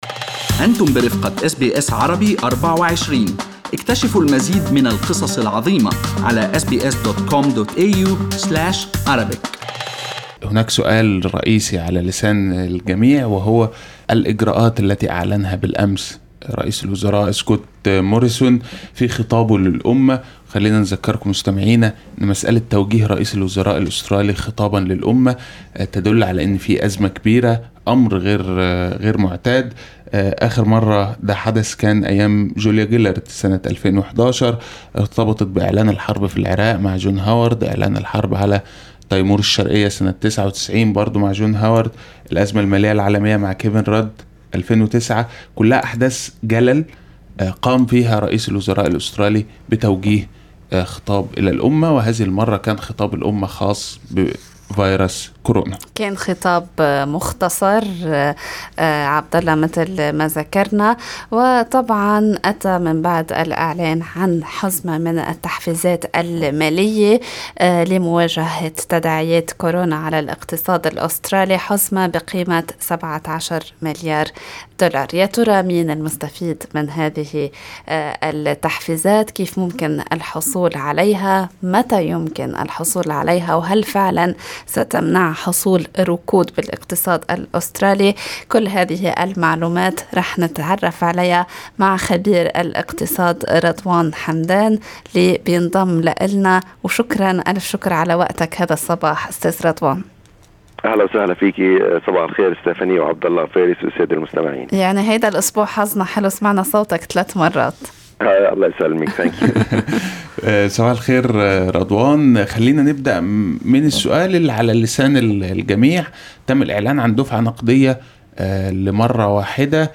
الخبير الاقتصادي